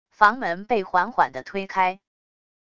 房门被缓缓的推开wav下载